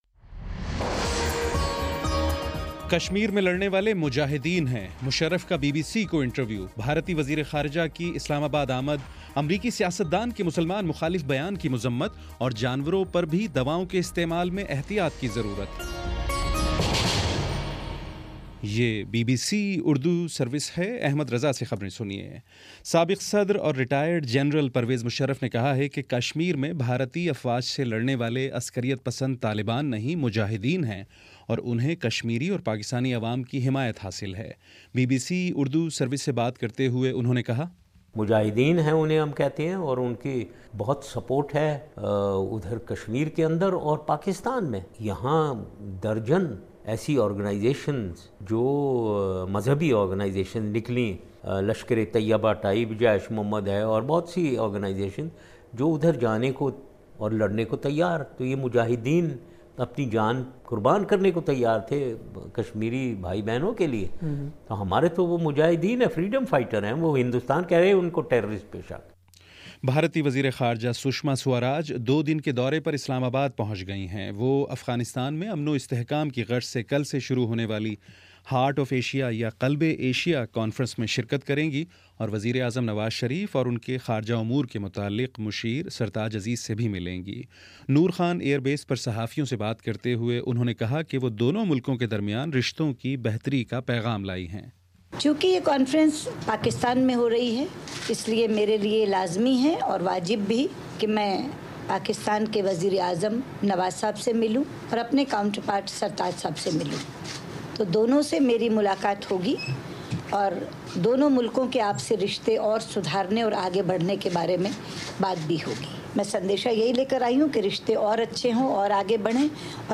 دسمبر 08 : شام چھ بجے کا نیوز بُلیٹن